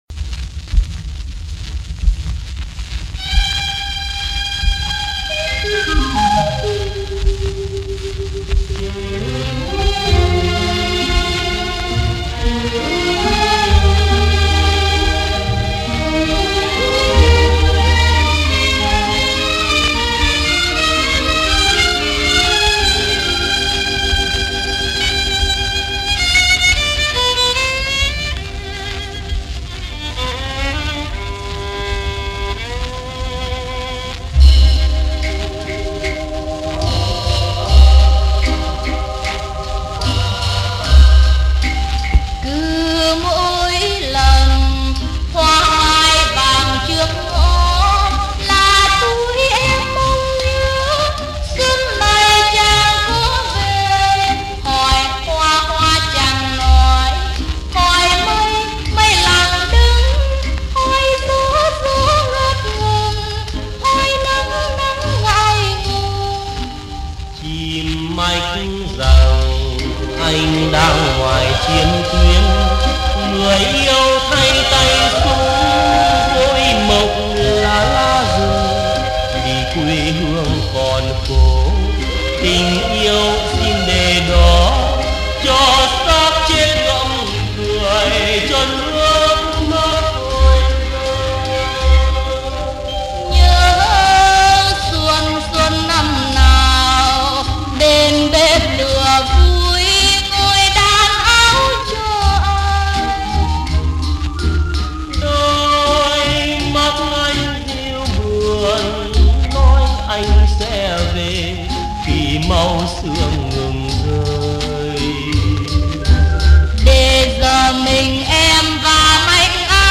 Lời nhạc bình dị, tiết tấu bolero êm đềm.